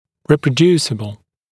[ˌriːprə’djuːsɪbl][ˌри:прэ’дйу:сибл]воспроизводимый, повторимый